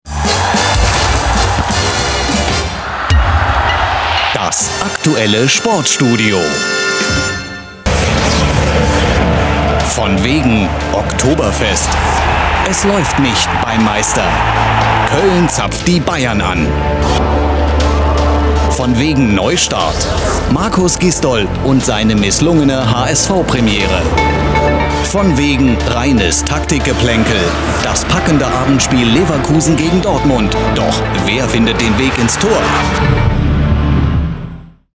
plakativ
Mittel minus (25-45)